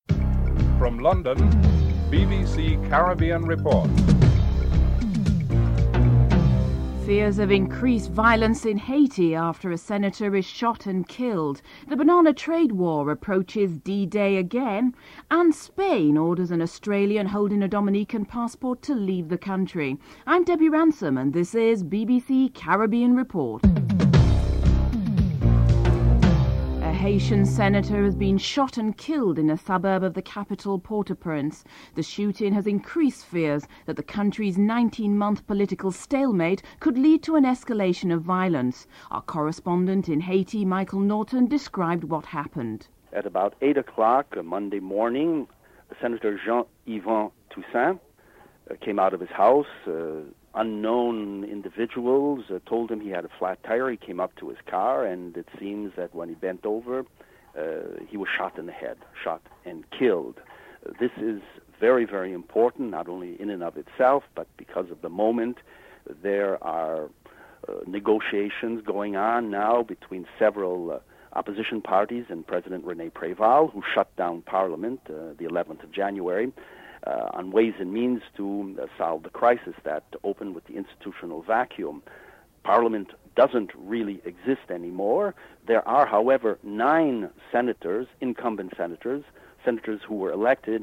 Opposition leader Osborne Fleming discusses confrontational politics and his choice of addressing political issues. Chief Minister Hubert Hughes predicts his victory in the polls.